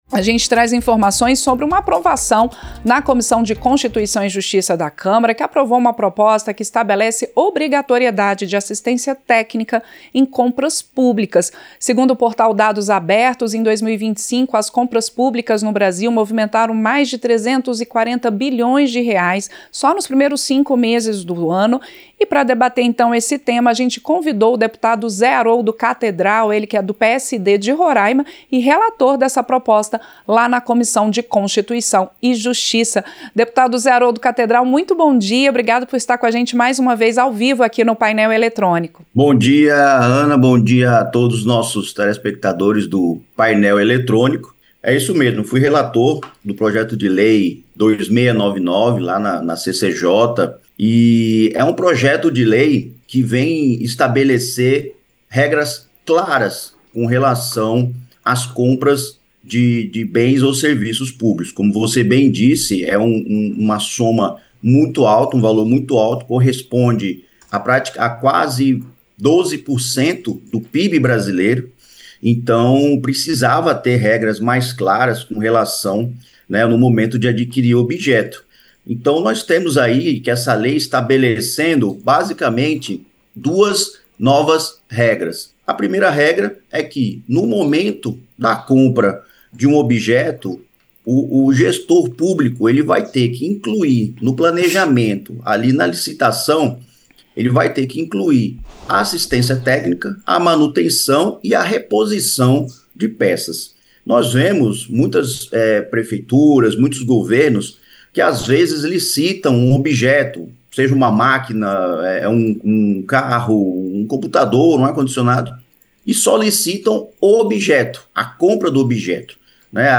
• Entrevista - Dep. Zé Haroldo Cathedral (PSD-RR)
Programa ao vivo com reportagens, entrevistas sobre temas relacionados à Câmara dos Deputados, e o que vai ser destaque durante a semana.